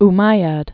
(-mīăd) or O·may·yad also Om·mi·ad (ō-mīăd)